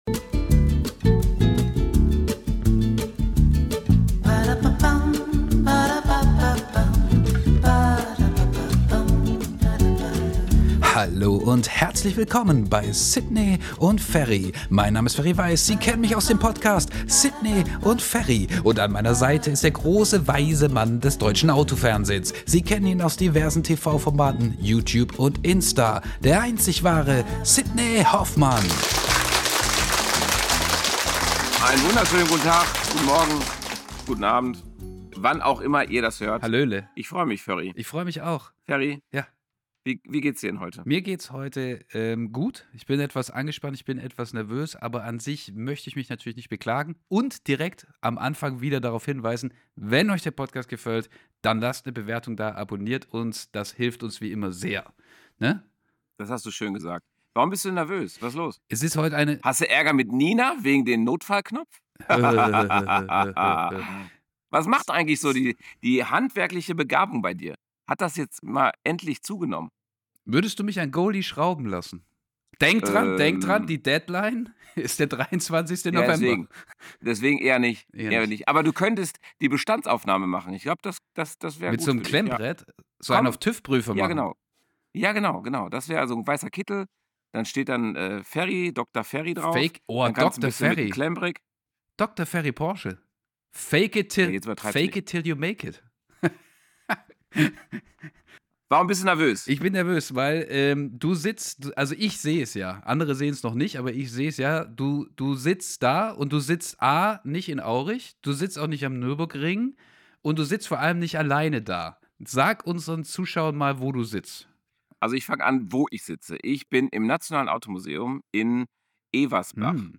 Die Folge ist durchzogen von Running Gags, gegenseitigem Aufziehen, Sammlerleidenschaft und einem echten Blick hinter die Kulissen eines spektakulären Automuseums.